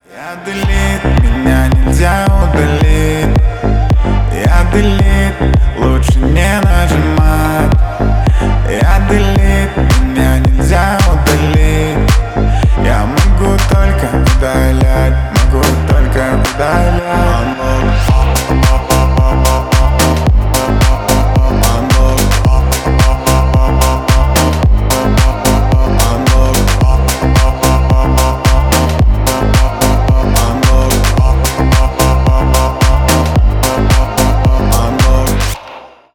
клубные
поп